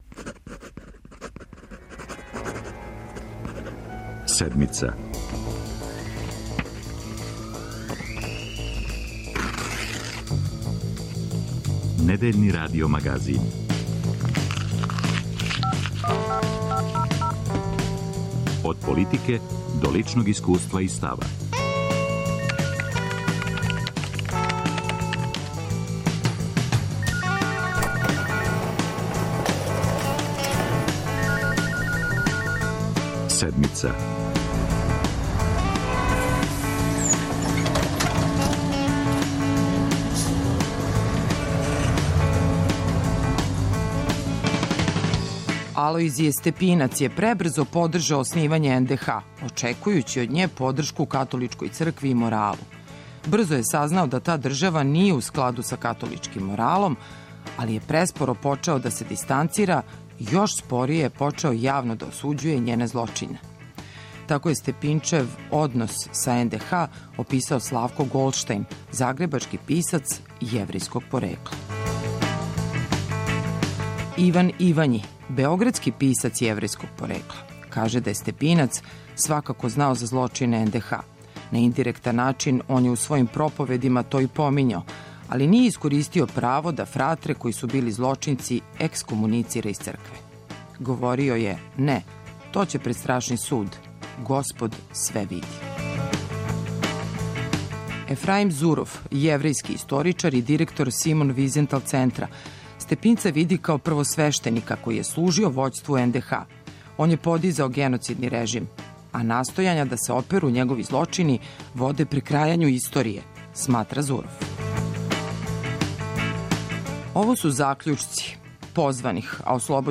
За Седмицу говоре историчари